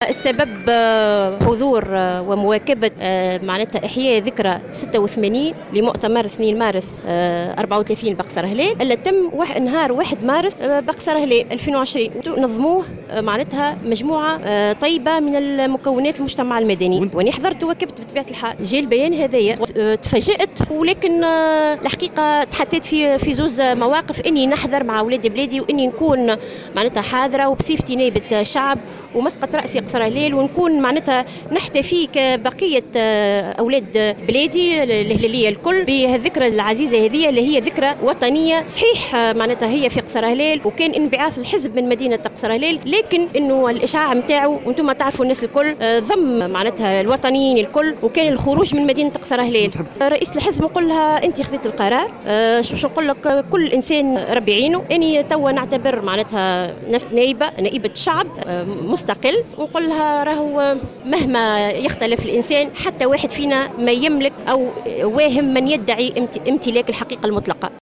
عقدت النائبة لمياء جعيدان اليوم الأربعاء ندوة صحفية في قصر هلال للرد على البيان الصادر عن الحزب الدستوري الحر بإبعادها وشطب اسمها من كتلة الحزب في البرلمان.